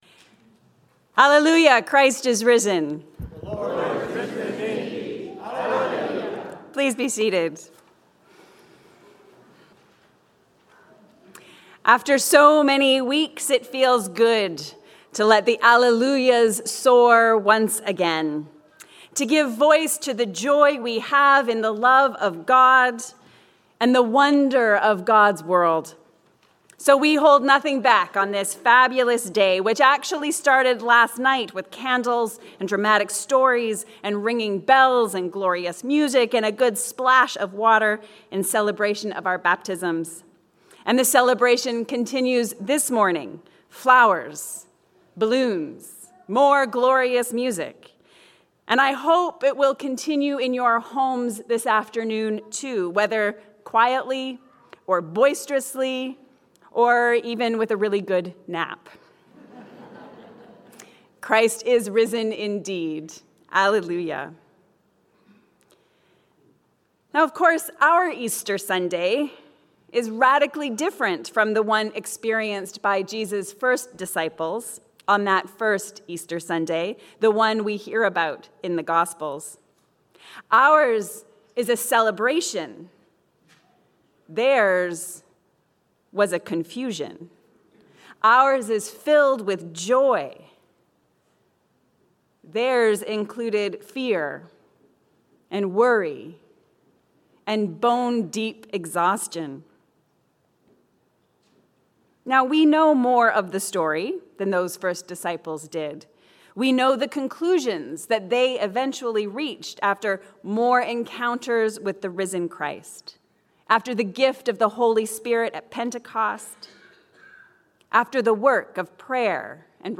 The Resurrection makes all things possible. A sermon for Easter Sunday